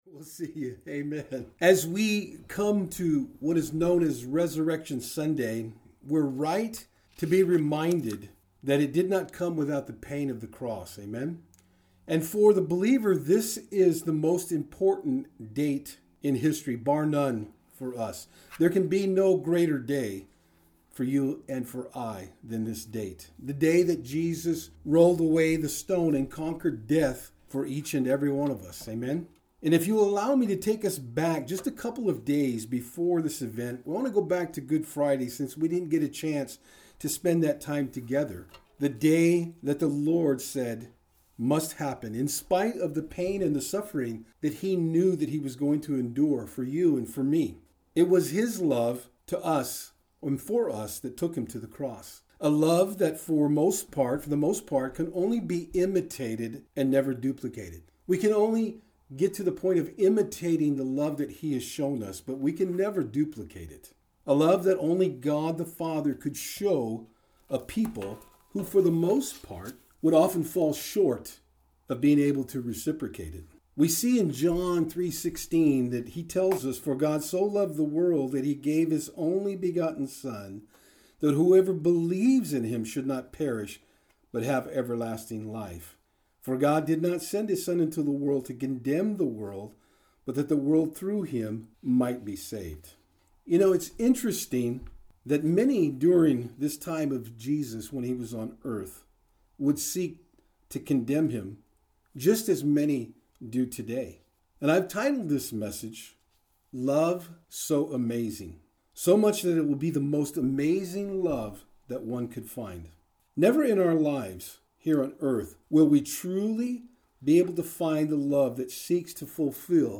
Easter-Message-4-12-20.mp3